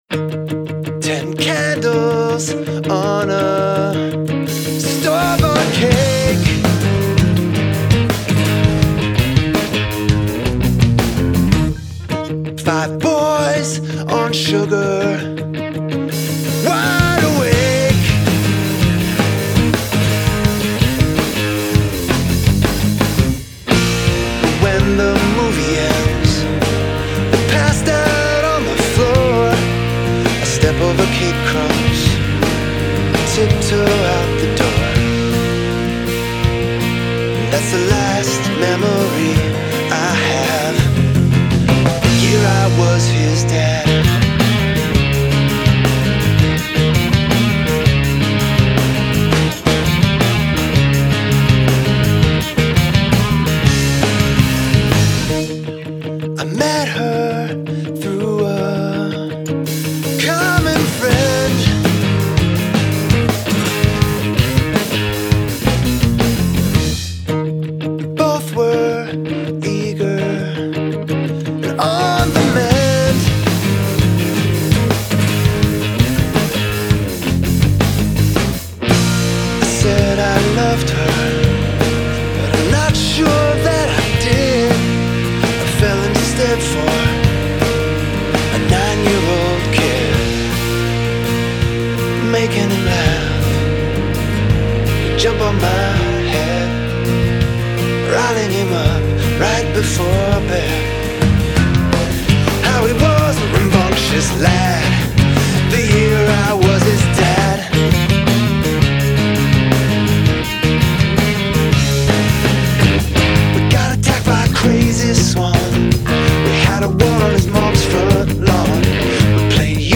- indie rock or alt-folk or even just call it music
drums